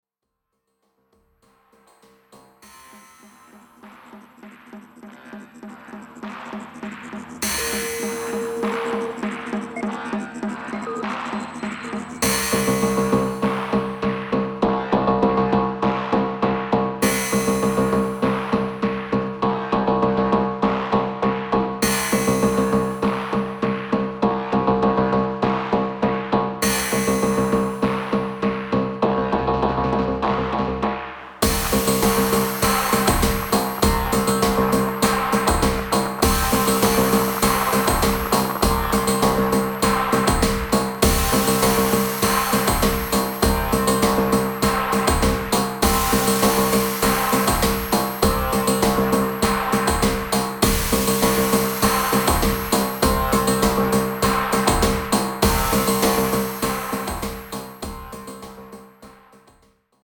今回もガムラン的なパーカッションアレンジに血湧き肉躍る熱帯生まれの新型インダストリアルを展開。